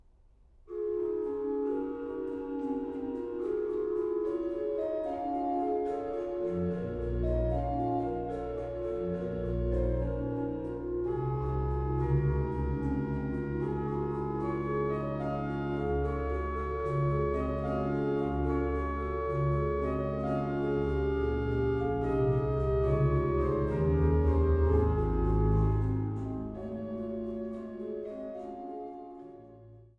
an der Großen Silbermann-Orgel im Freiberger Dom
Orgel